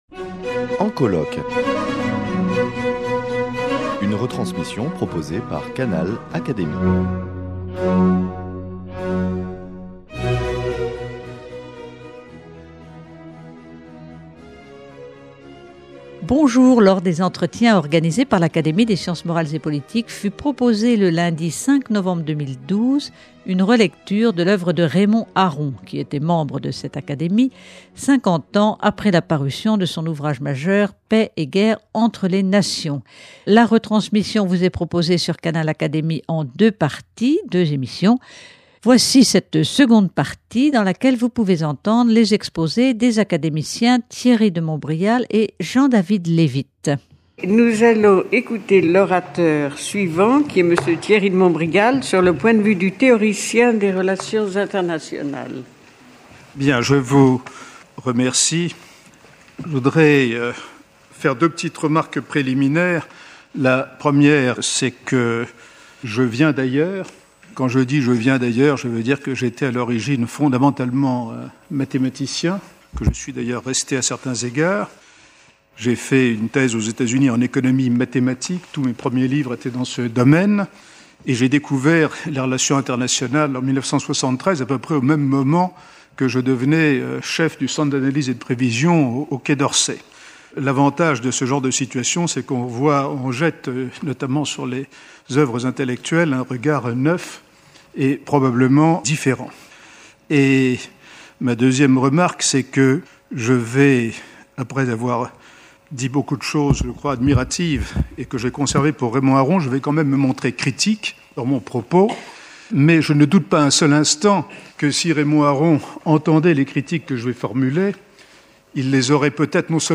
Dans cette retransmission des Entretiens de l’Académie des sciences morales et politiques du lundi 5 novembre 2012, sont exposés plusieurs points de vue sur la fécondité de la pensée de Raymond Aron. Ecoutez ici les interventions de Thierry de Montbrial, spécialiste des relations internationales, et de Jean-David Levitte, diplomate, tous deux membres de l’Académie.